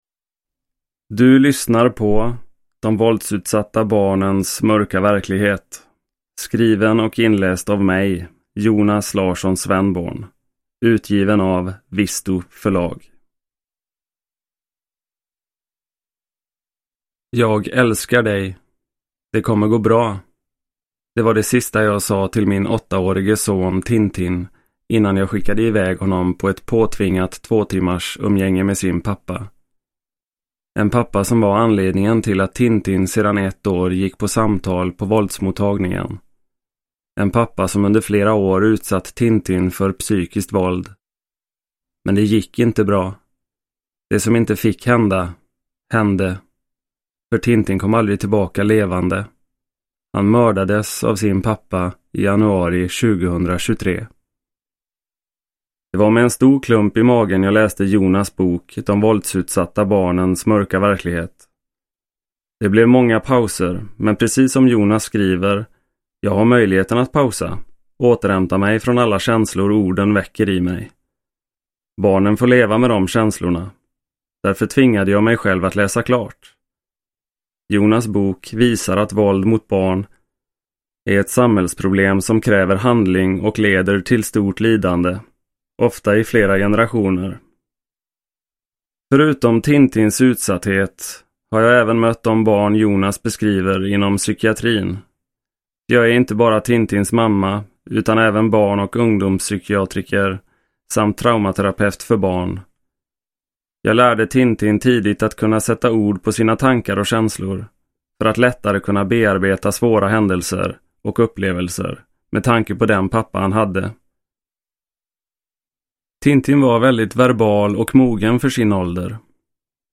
De våldsutsatta barnens mörka verklighet – Ljudbok